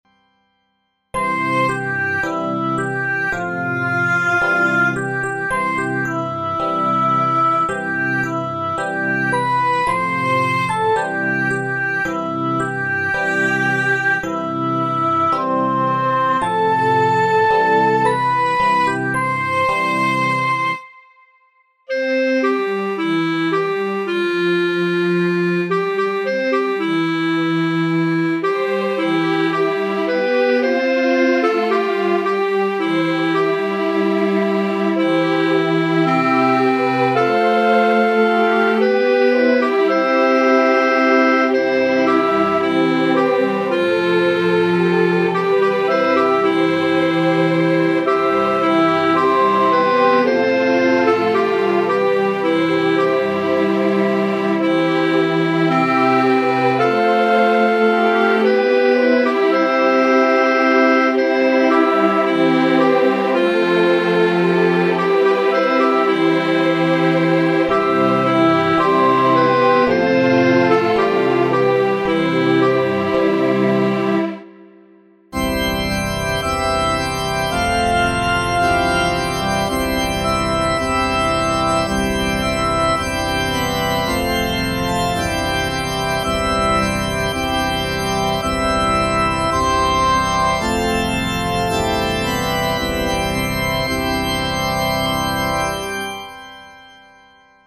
Kanon, 3-stimmig C-Dur